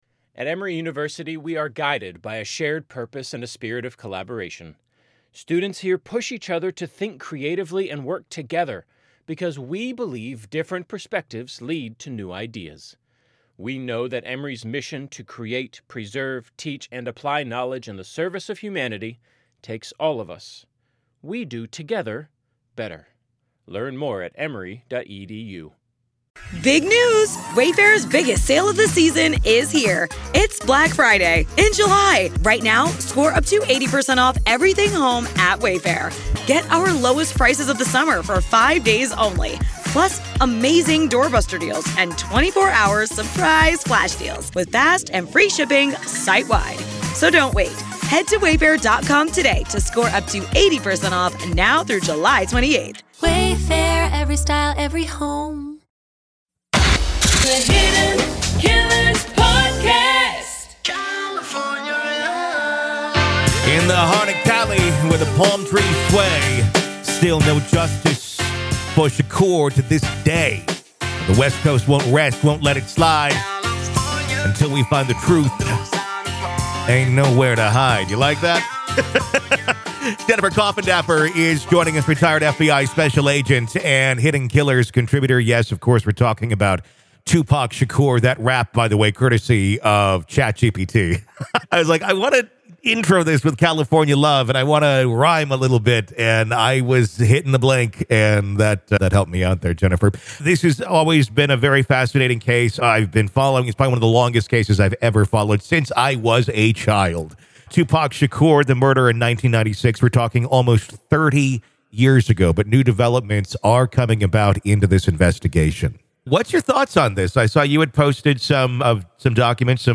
The conversation turns towards recent leads brought about by the examination of previously seized items from the house of Keffe D, a key person of interest.